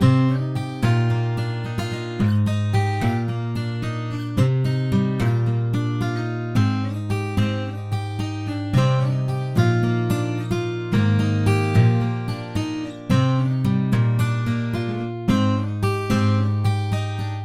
Tag: 110 bpm Trap Loops Guitar Acoustic Loops 2.94 MB wav Key : Cm Audition